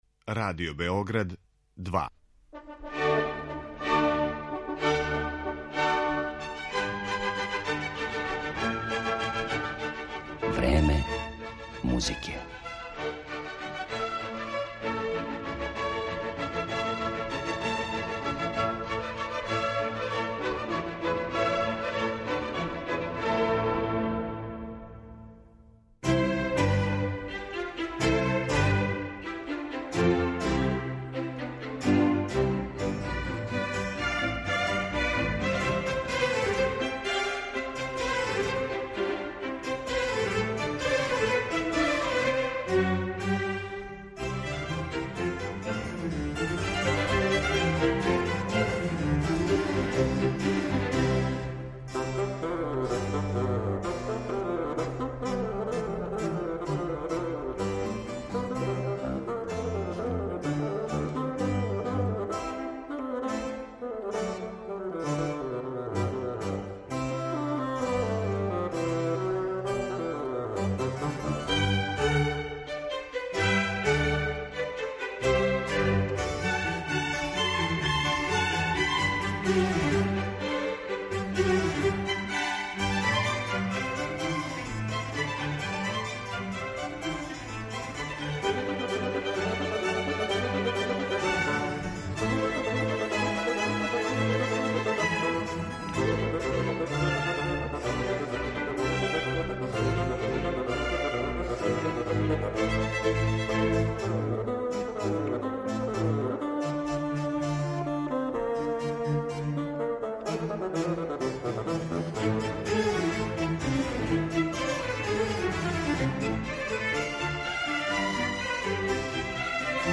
Музика за фагот